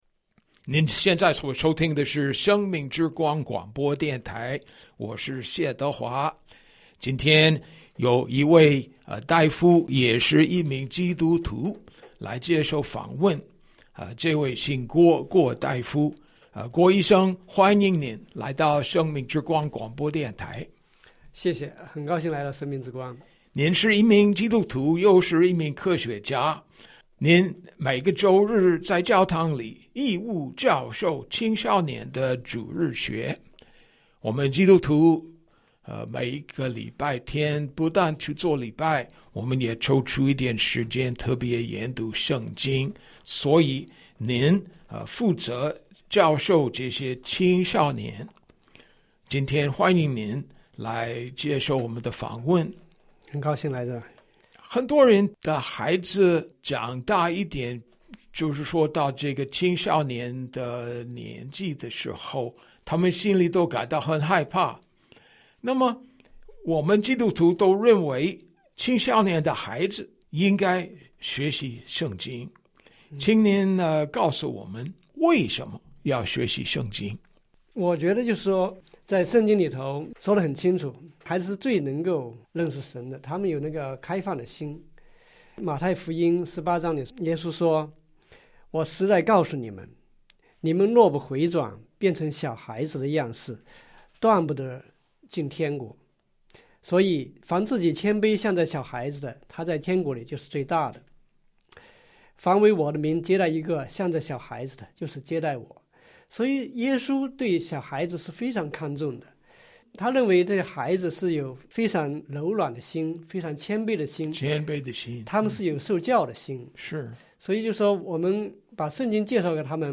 訪談